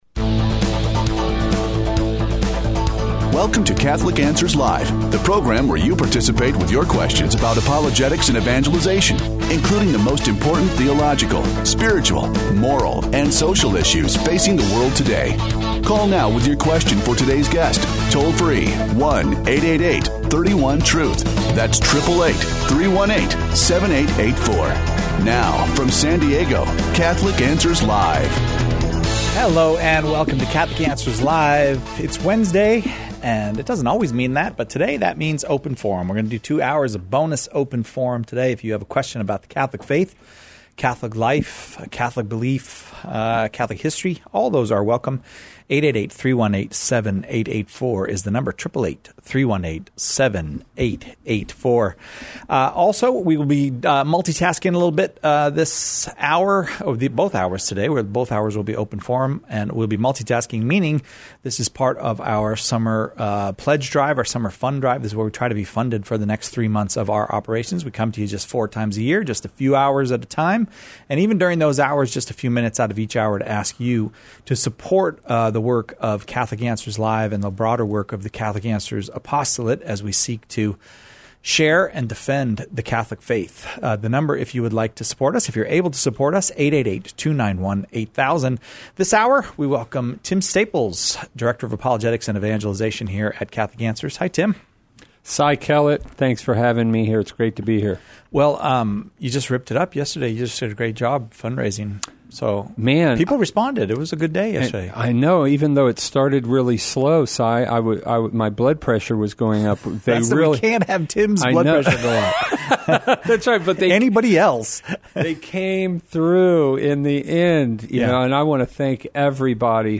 We are continuing on day 2 of our quarterly pledge drive.